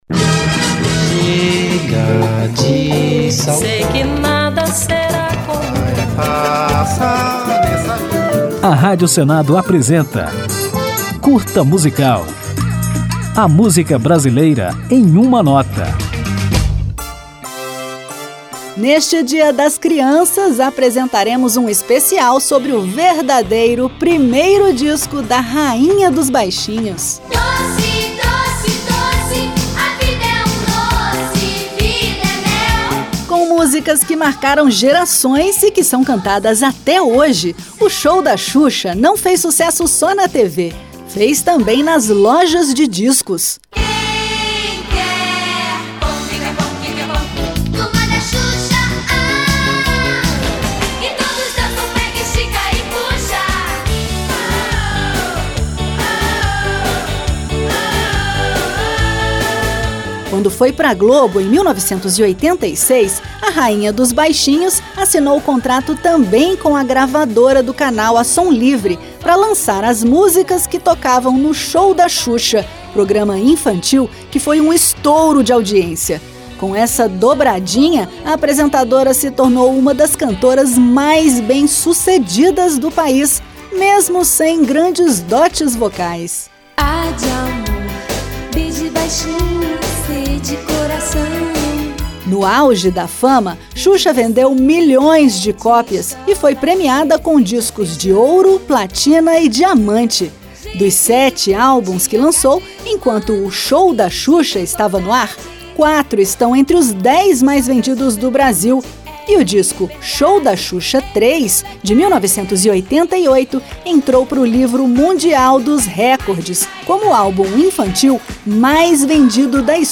Ao final, ouviremos a Rainha dos Baixinhos na música Sete Quedas, composta especialmente para a estreia de Xuxa como cantora.